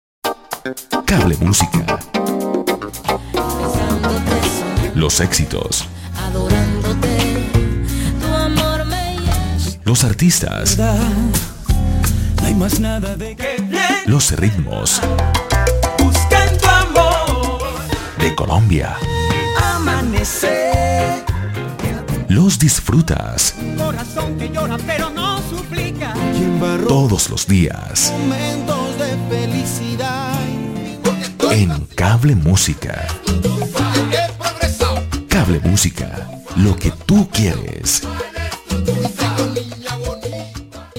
Voz en OFF Cable Música
Comercial_2_Voz_en_Off.mp3